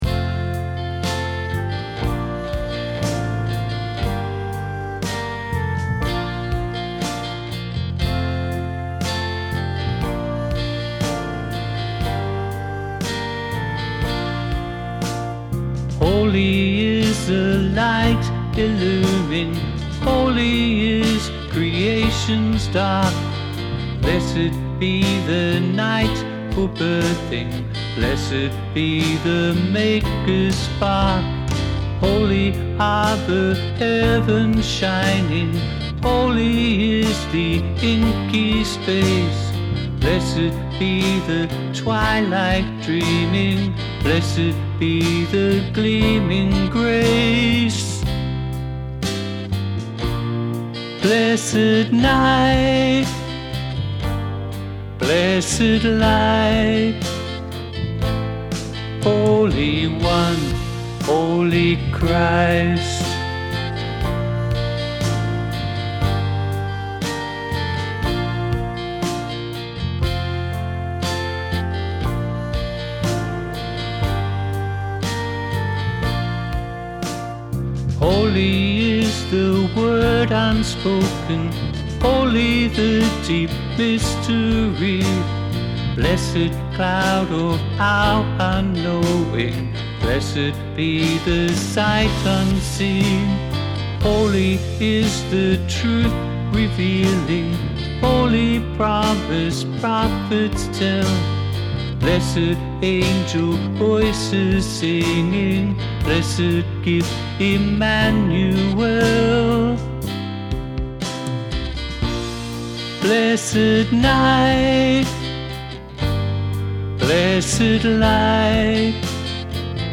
Note that the timing is different from the score in a couple of places and the last verse and chorus go up a tone.
Blessed_Night_Blessed_Light_VOCAL.mp3